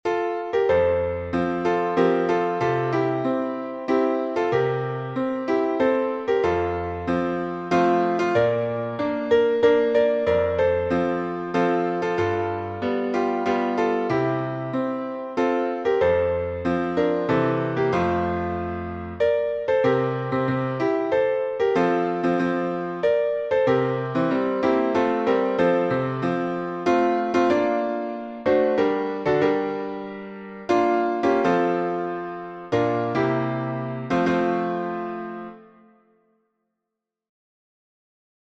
Key signature: F major (1 flat) Time signature: 3/4